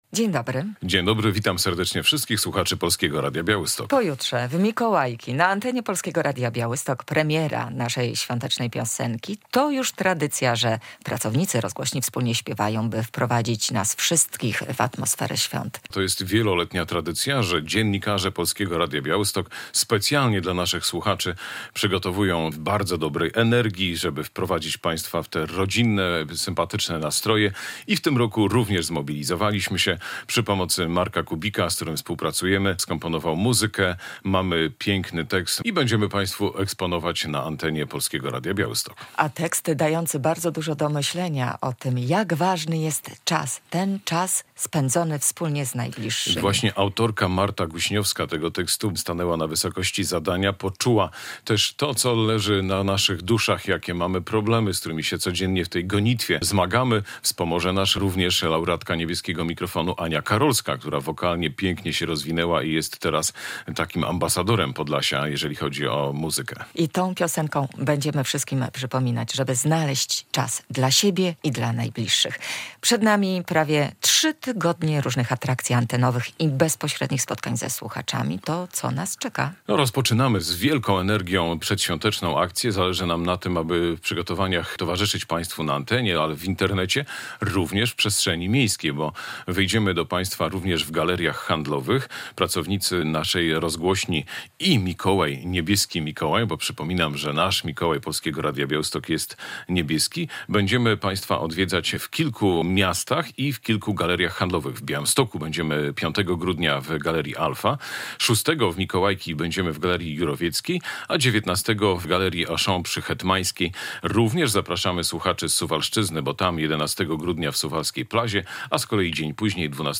Radio Białystok | Gość | Święta coraz bliżej - Polskie Radio Białystok zaprasza na konkursy, spotkania i niespodzianki -